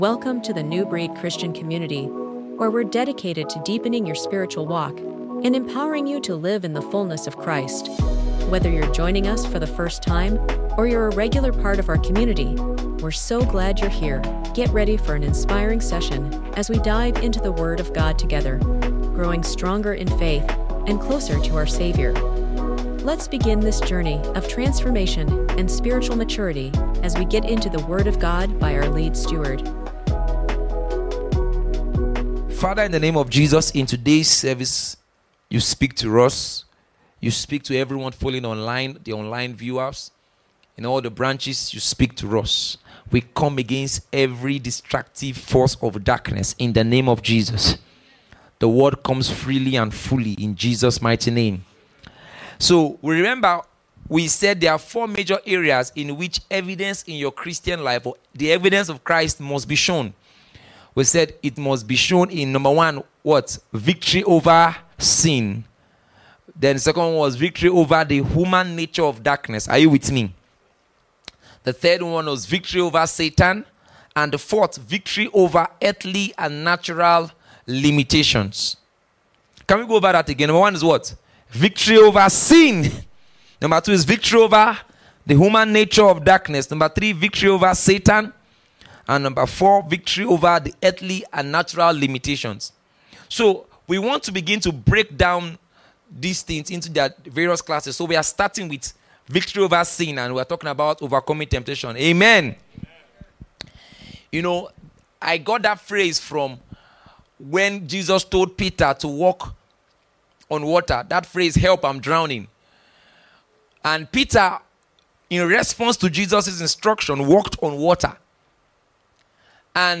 I'm Drowning | Overcoming Temptations 1” Watch on YouTube Listen on Spotify Download Sermon Other Sermons May 21, 2025 Help!